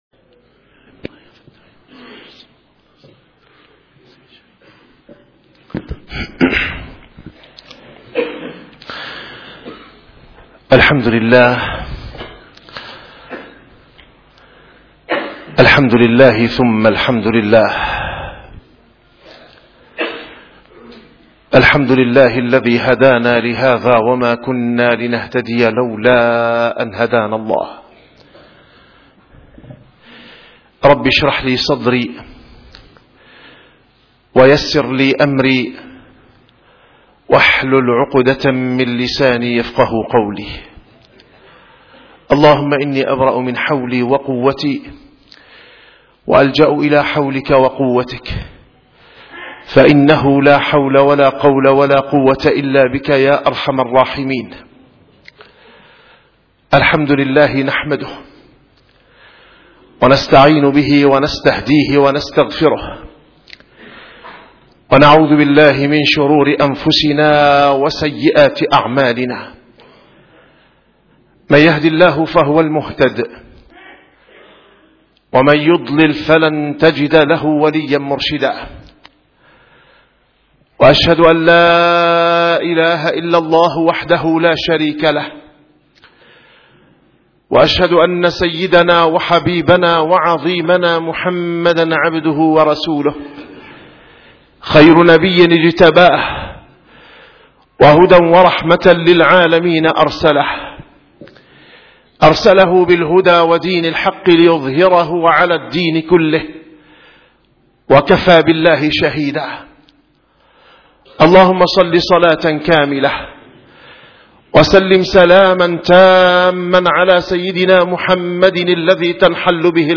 - الخطب - مقومات الدعوة إلى الله وثوابتها المنهجية للداعية المسلم